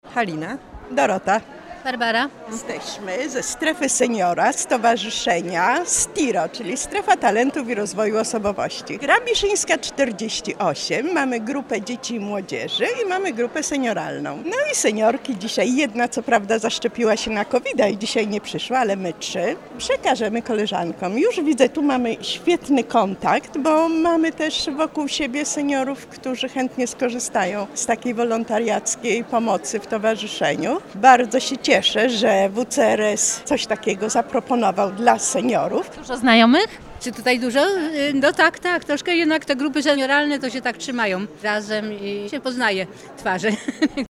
Zapytaliśmy uczestników o ich wrażenia.